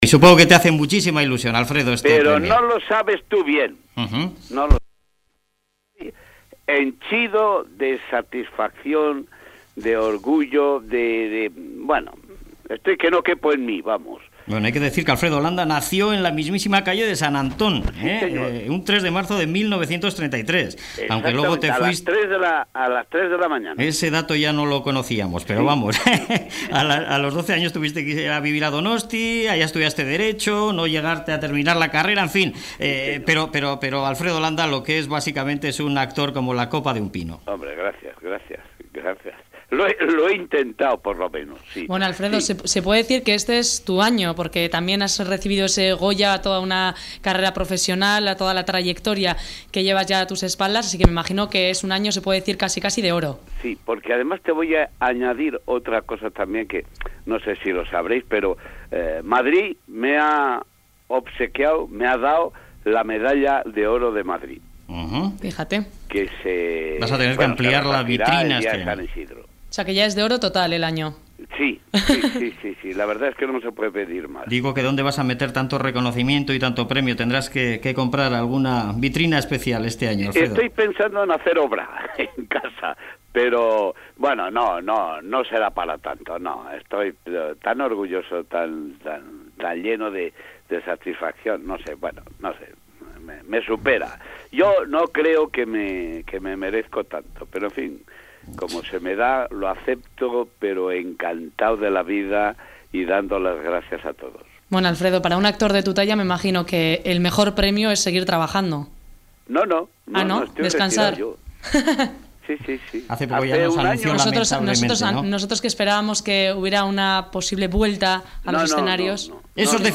Entrevista de Alfredo Landa a ‘Boulevard’ en 2008